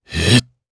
Lusikiel-Vox_Casting1_jp.wav